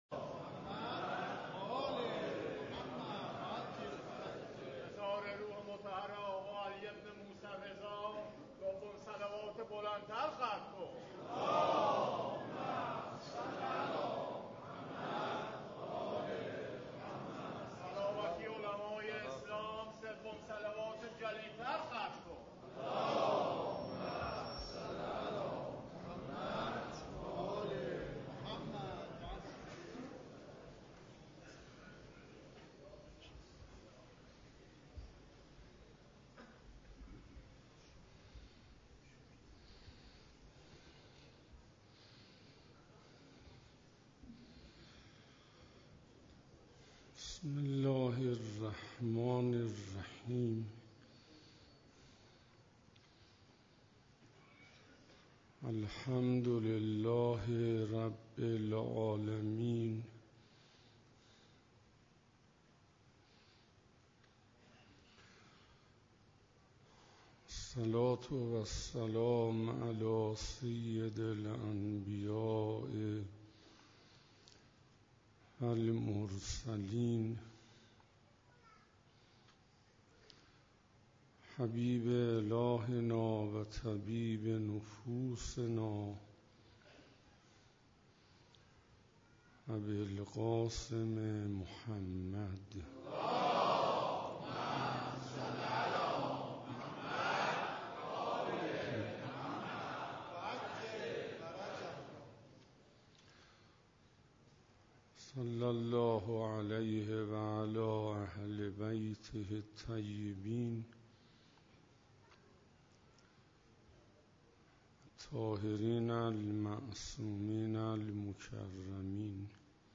شب 7 رمضان97 - حسینیه همدانی ها